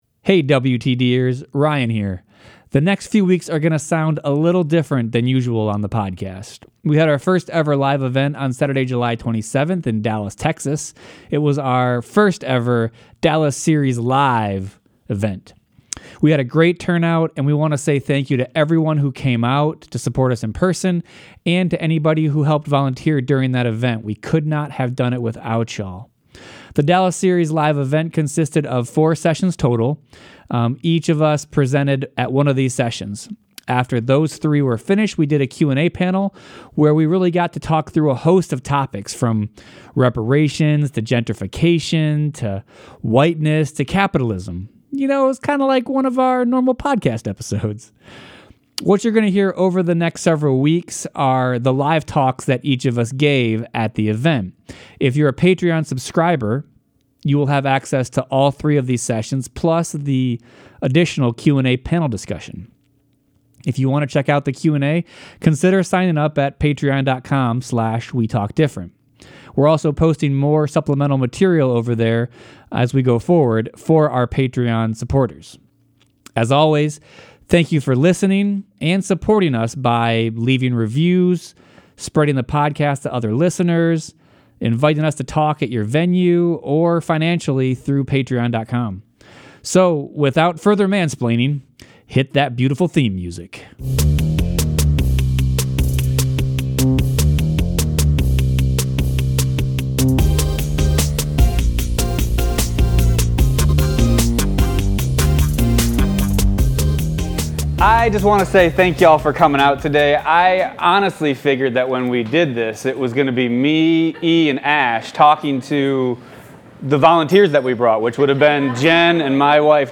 this is a talk